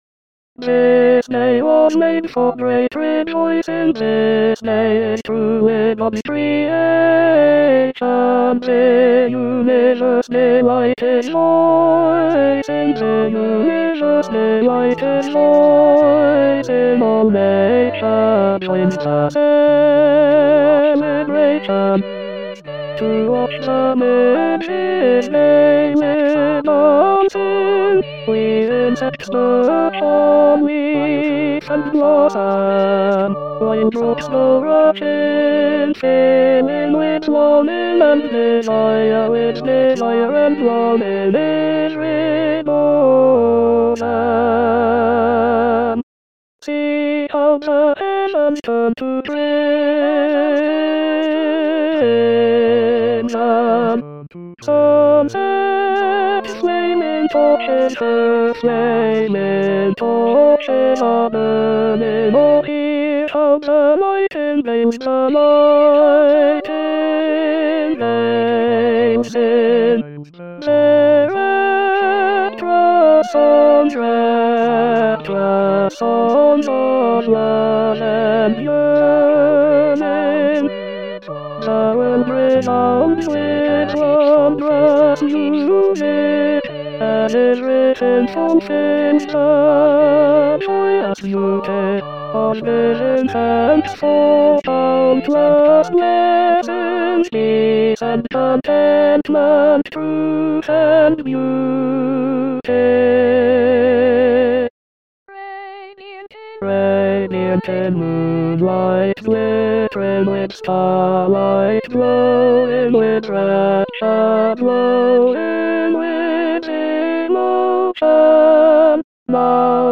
Tenor Tenor 1